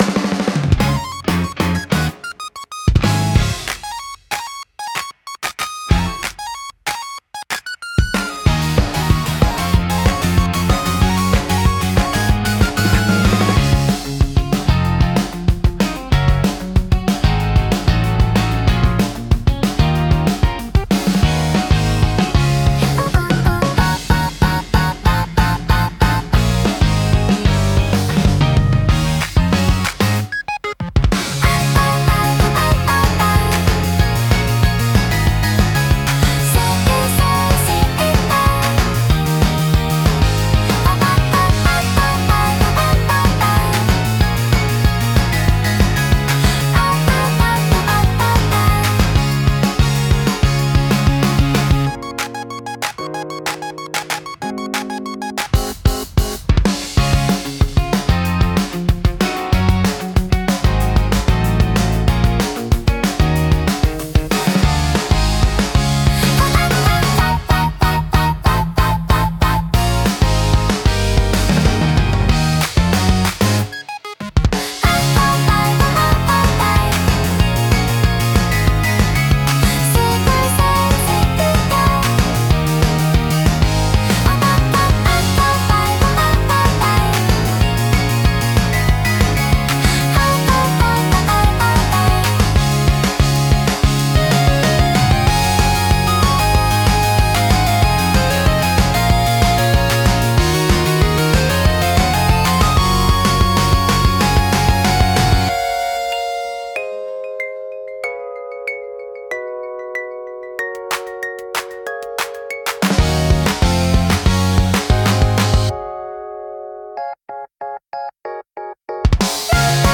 キュートコアは、明るくポップで可愛らしいメロディとエネルギッシュなビートが融合したオリジナルジャンルです。
キャッチーなフレーズと元気なリズムが特徴で、聴く人に楽しい気分を与えます。
元気で前向きな印象を与え、若年層やファミリー向けコンテンツに特によく合います。